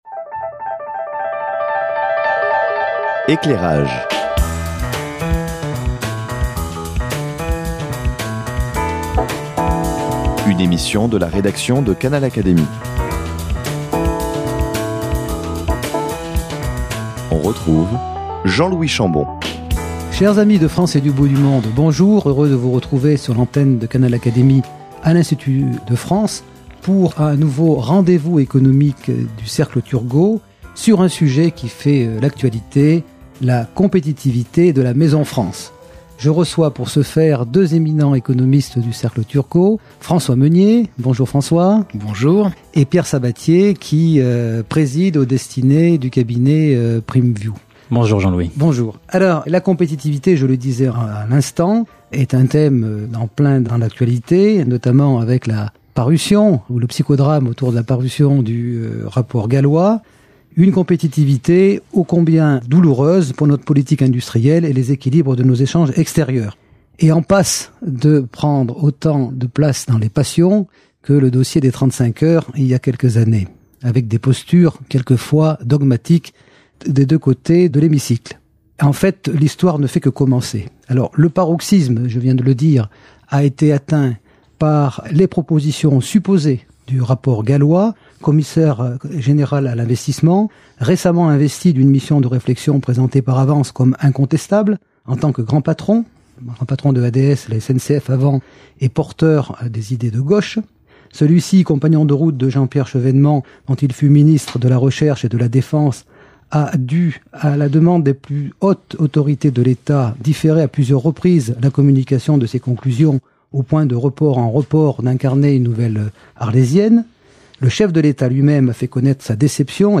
Invités à débattre sur la théorie économique du "choc", à l’épreuve de la doctrine gouvernementale